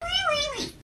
Рингтоны на СМС
мяу
милые звуки